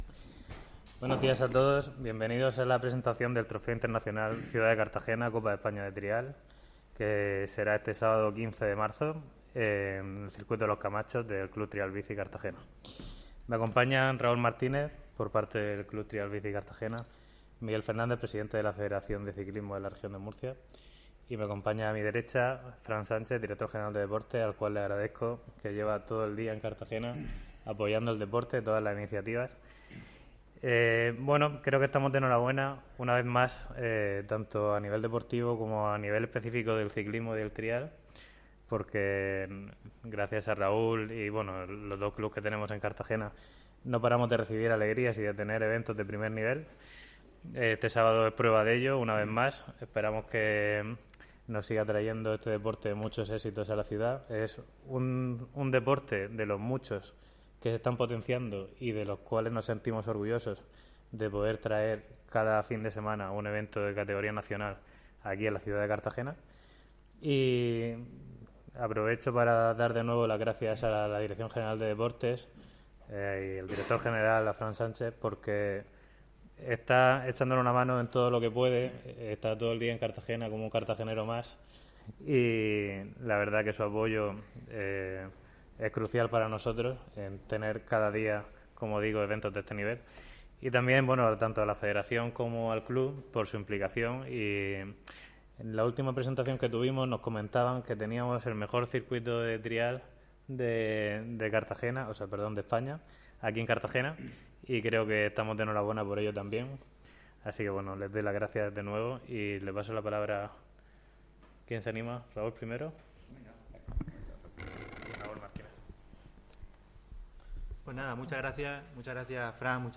Enlace a Presentación del Trofeo Internacional Ciudad de Cartagena de Trial bici